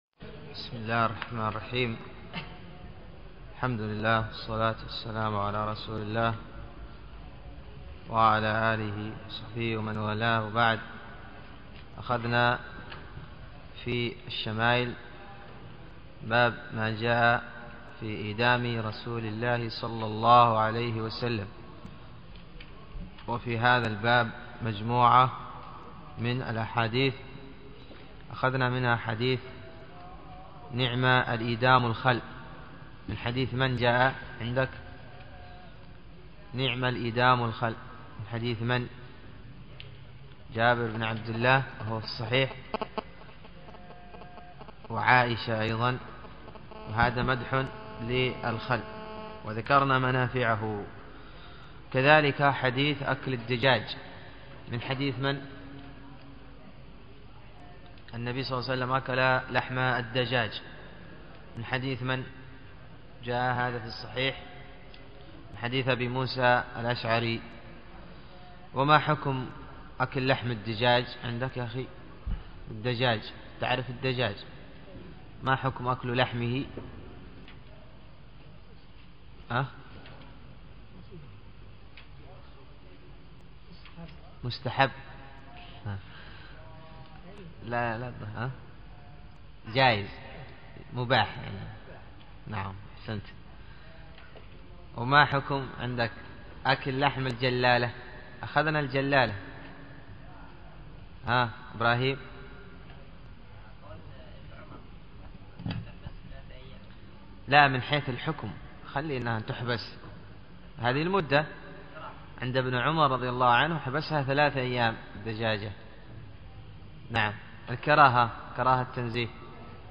الدرس الثالث و الثلاثون من دروس الشمائل المحمدية